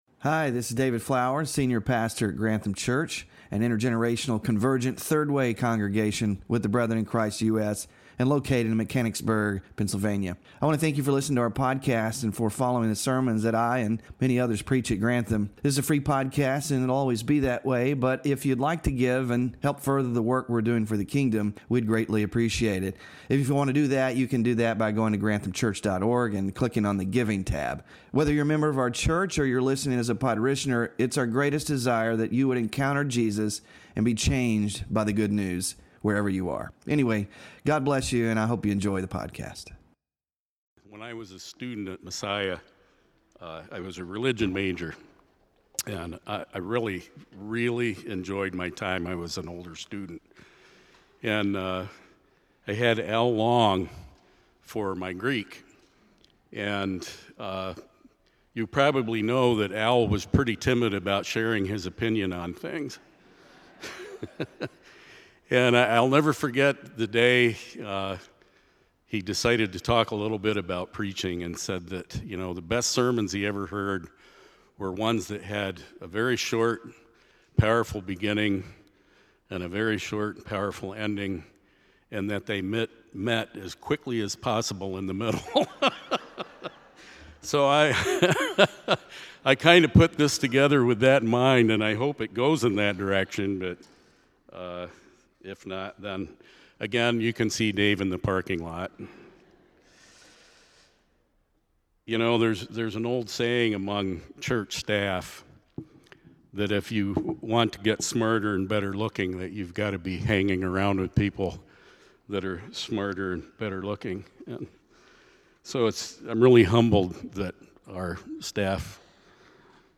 FRUIT OF THE SPIRIT WK4-PATIENCE SERMON SLIDES